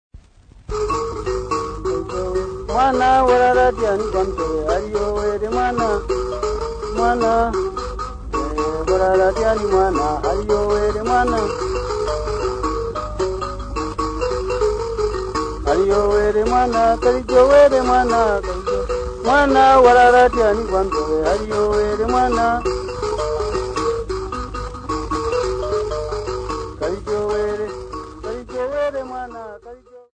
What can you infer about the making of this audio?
Field recordings Africa Zambia Lusaka f-za